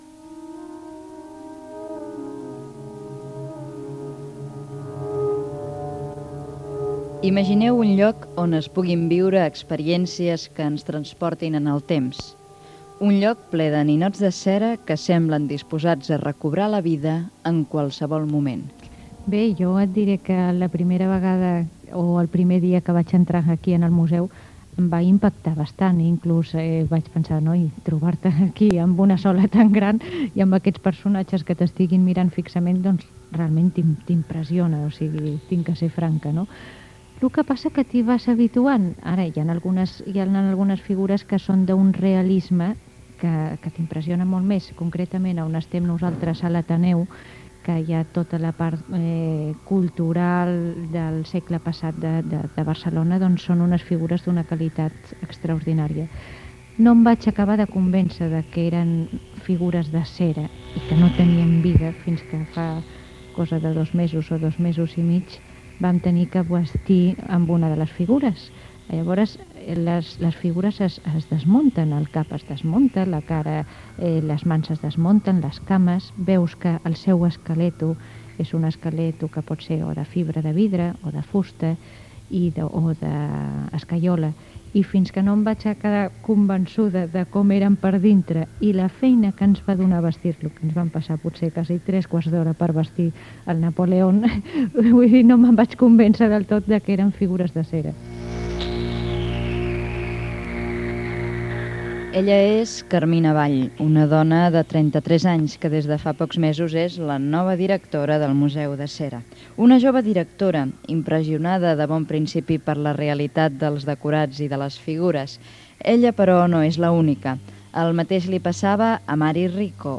Reportatge sobre el Museu de Cera de Barcelona